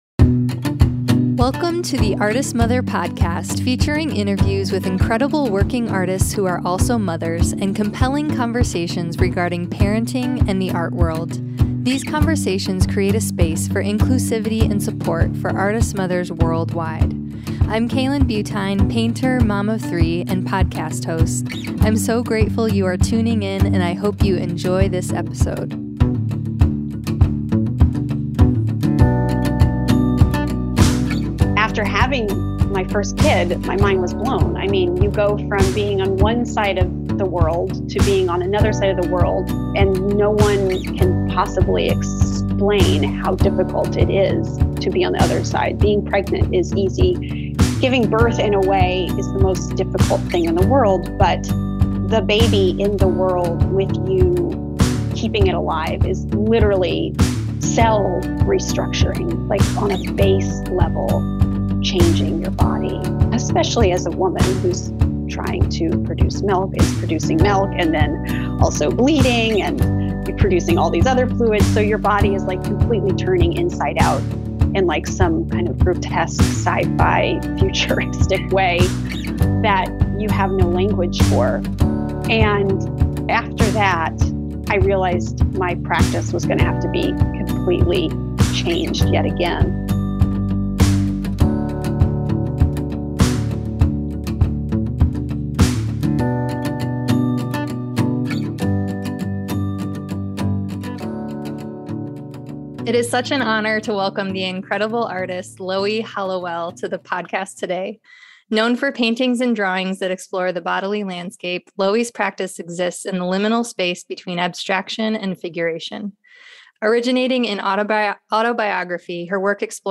116: What Kind of Support is Needed for Artists to Have Children? A Conversation with Loie Hollowell